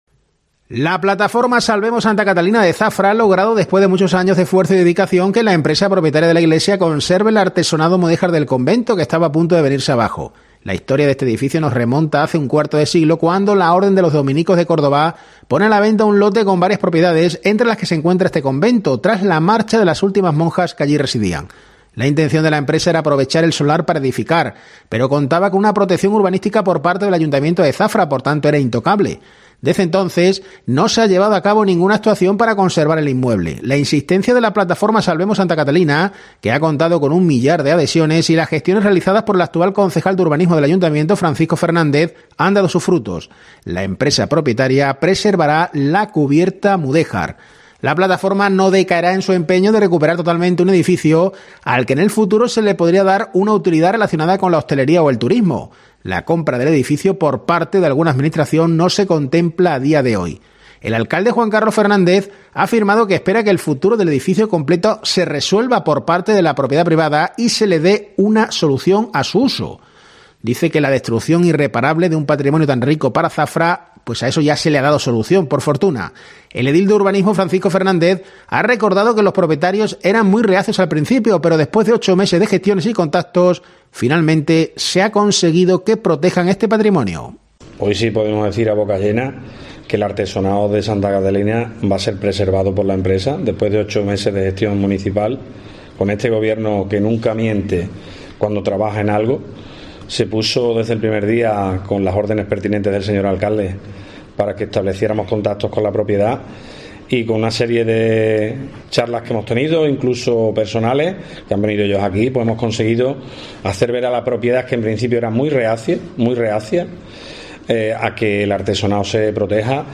En COPE, hemos hablado con el primero de ellos.